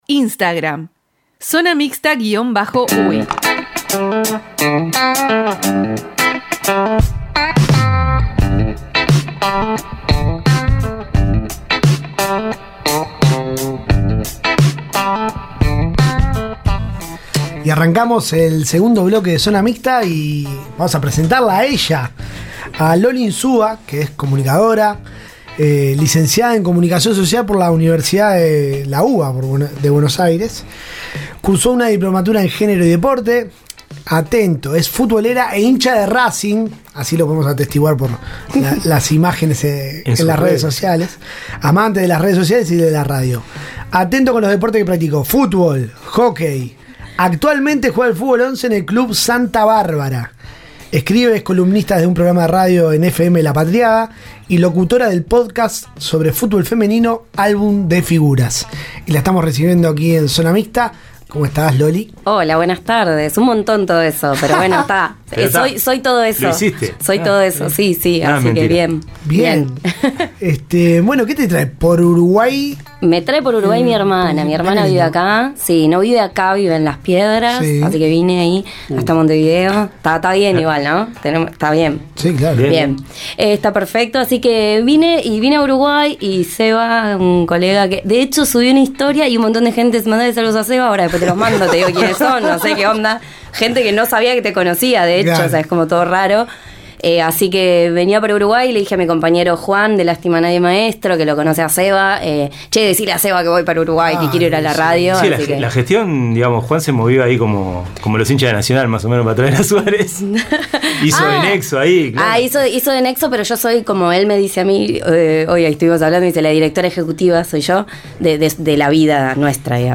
Zona Mixta: entrevista